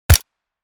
Hundreds of professionally recorded War Sound Effects to download instantly, from Pistols, Missiles and Bombs, Guns, Machine guns and Sniper Rifles!
M16-assault-rifle-suppressed-shot.mp3